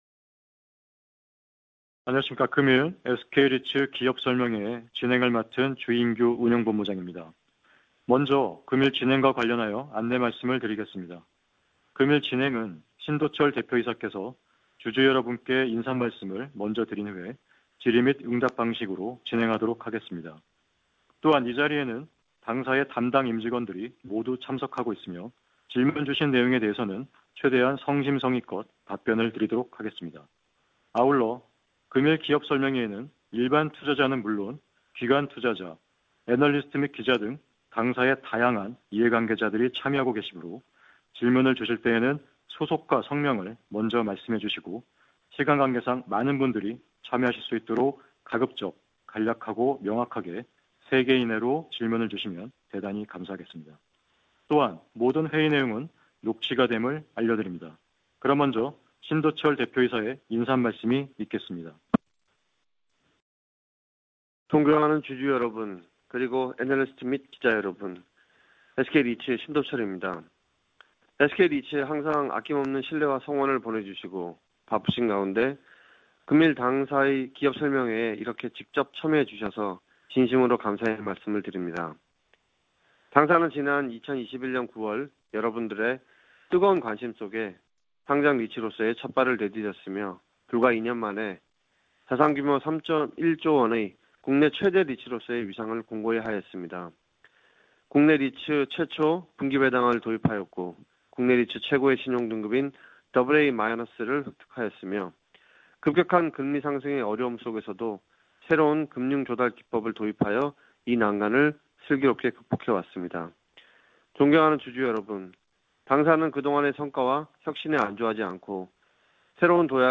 SK리츠 기업설명회(IR) 결과 공유 (컨퍼런스콜 녹화본)